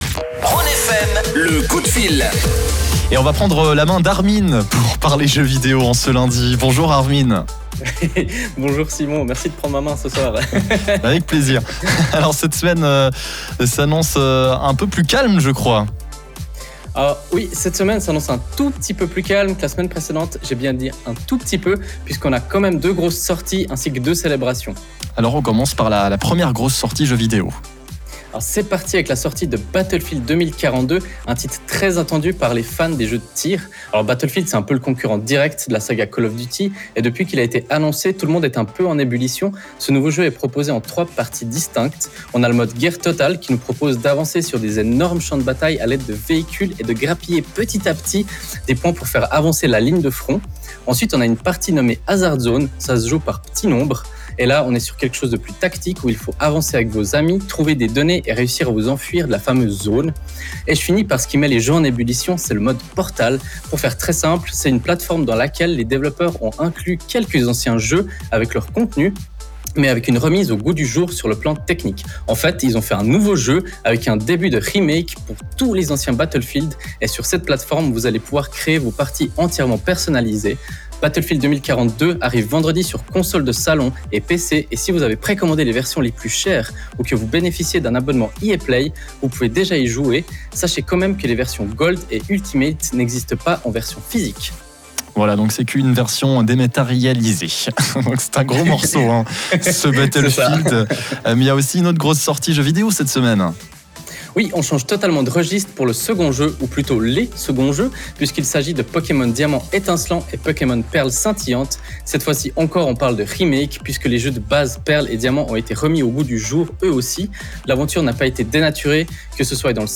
Comme tous les lundis désormais, nous avons la chance de présenter une petite chronique gaming sur la radio Rhône FM. Cette semaine on se dirige sur deux sorties majeures, mais aussi sur deux célébrations, dont une, en Suisse.